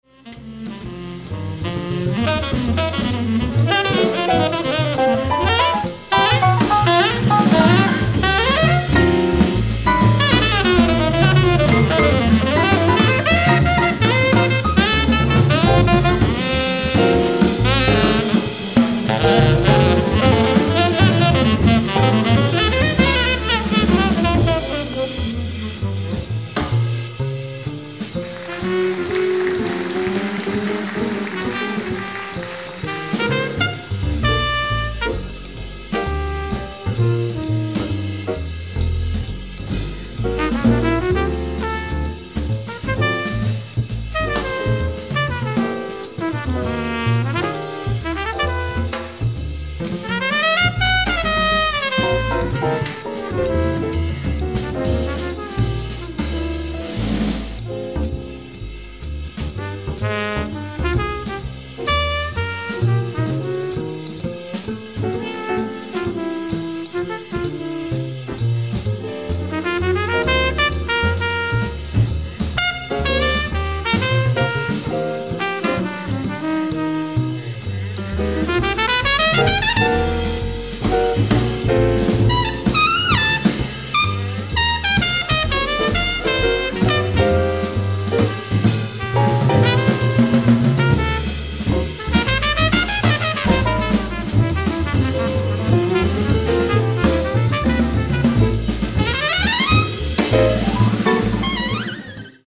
ライブ・アット・モルデ・ジャズフェスティバル、ノルウェー 07/17/2002
※試聴用に実際より音質を落としています。